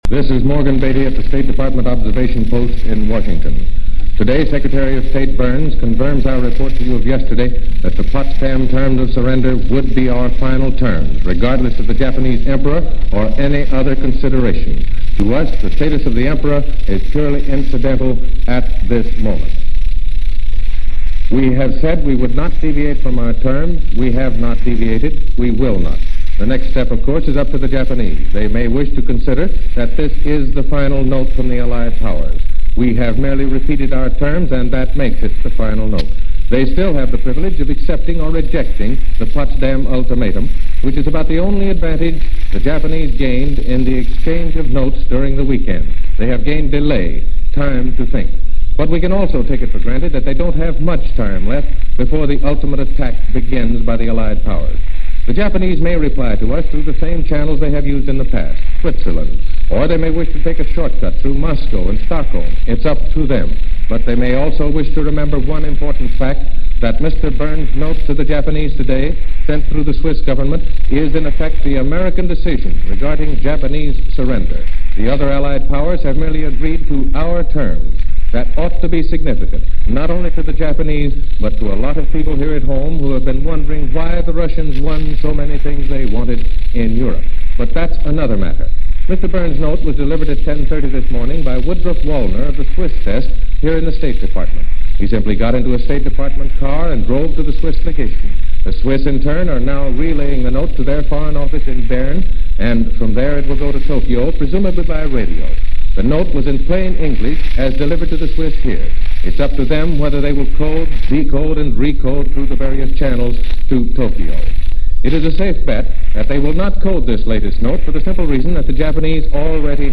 Special Report by Morgan Beatty, State Department
To give an update, some word of progress, NBC interrupted its regular programs with a special broadcast by reporter Morgan Beatty, who was camped outside the State Department, waiting for official word that the Japanese accepted unconditional surrender terms.